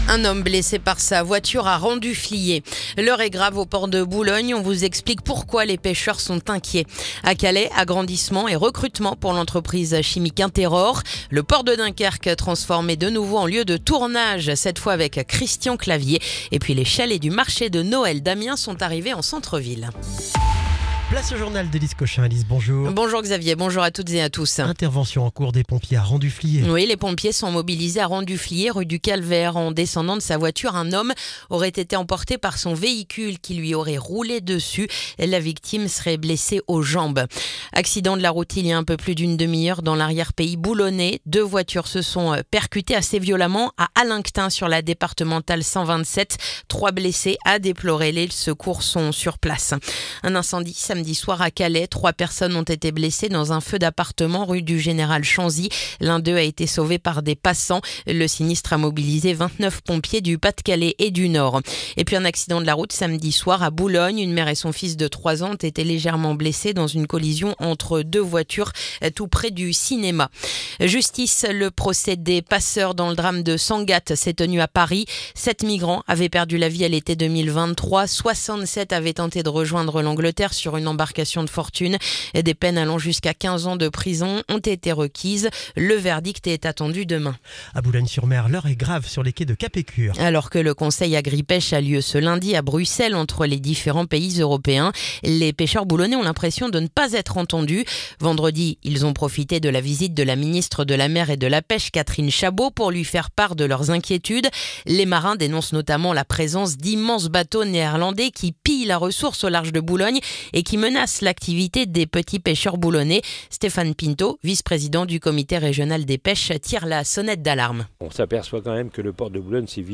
Le journal du lundi 17 novembre